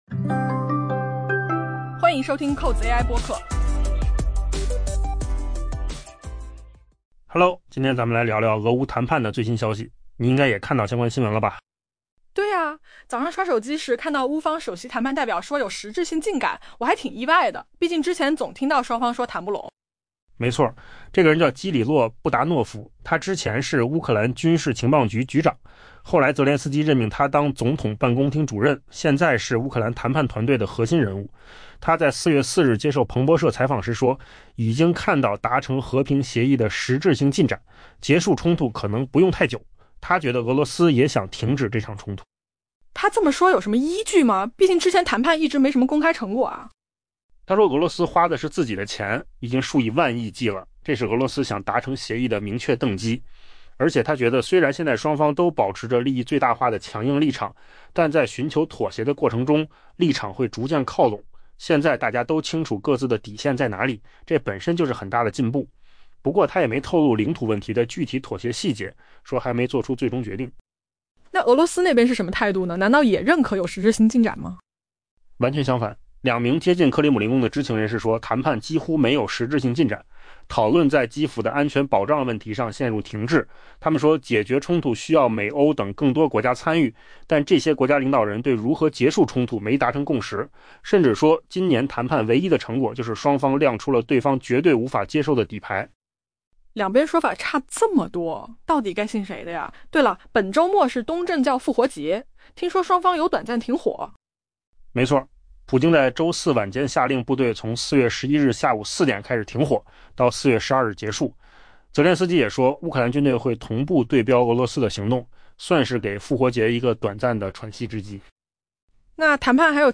音频由扣子空间生成